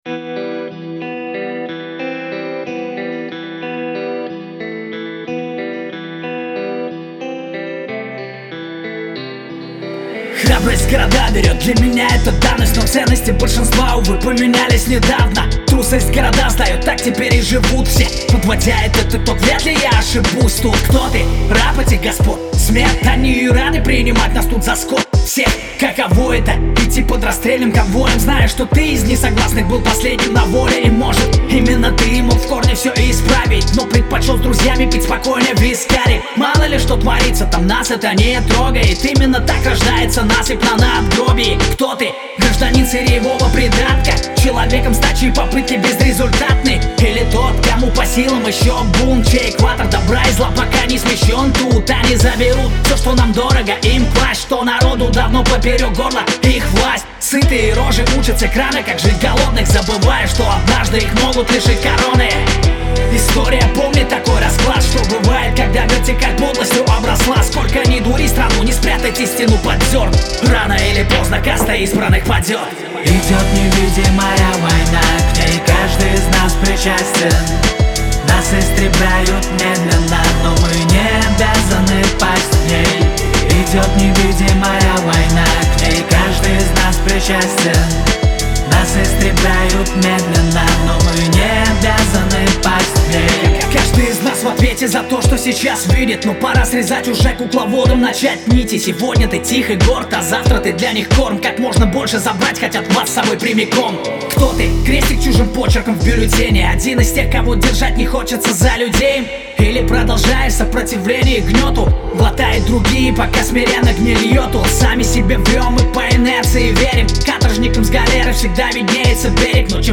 сочетая агрессивные биты с лирическими текстами.